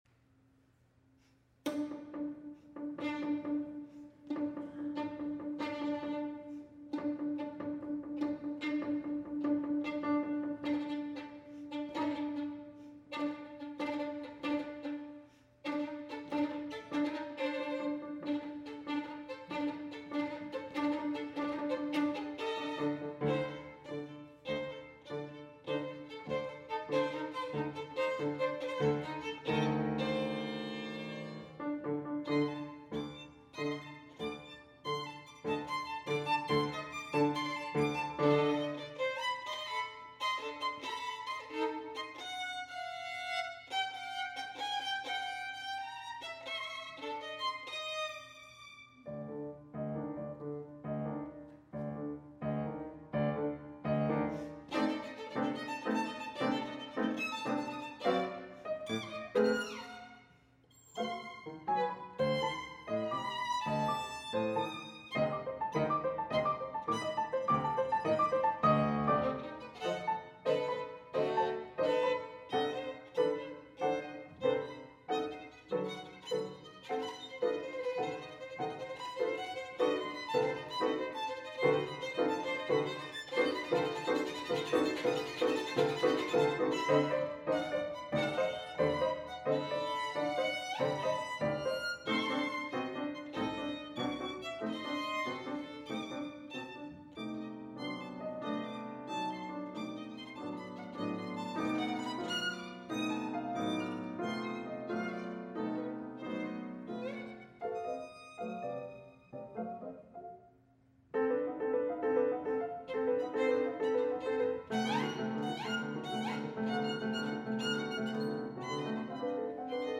Instrumentation: Violin and piano